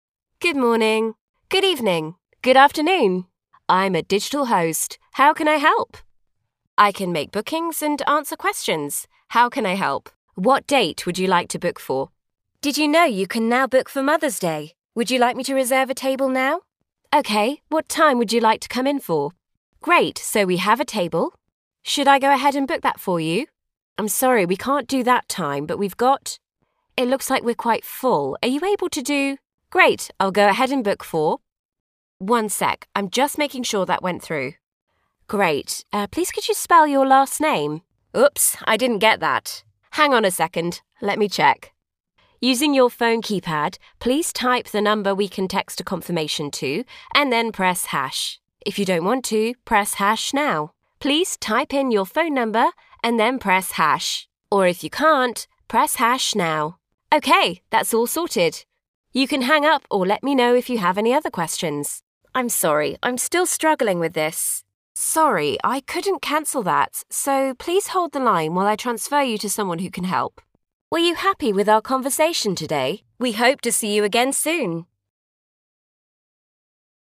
IVR
Mitreißend, inspirierend, natürlich und klar mit erstaunlicher Bandbreite und Vielseitigkeit.
Mikrofone: Neumann U87, Neumann TLM 102, Sennheiser MKH 416
Kabine: Doppelwandige, maßgeschneiderte Gesangskabine von Session Booth mit zusätzlichen Paneelen von EQ Acoustics, Auralex und Clearsonic.